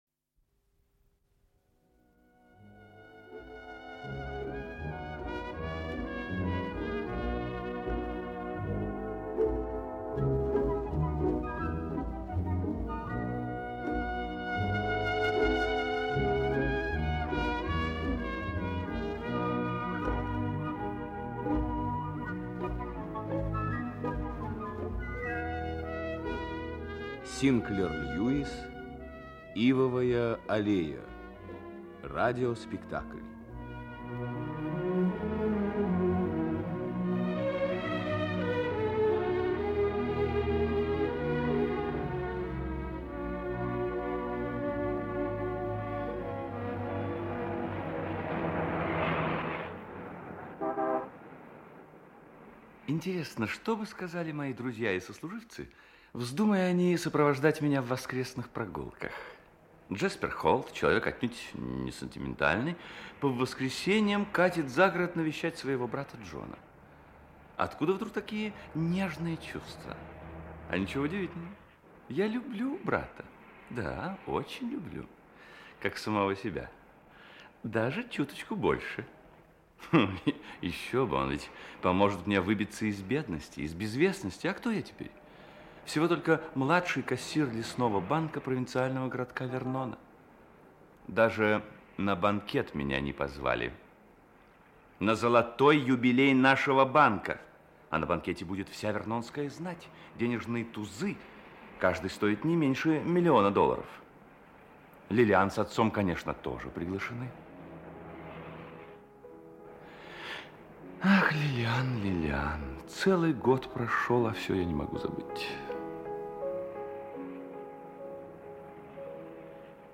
Аудиокнига Ивовая аллея. Часть 1 | Библиотека аудиокниг
Часть 1 Автор Льюис Синклер Читает аудиокнигу Актерский коллектив.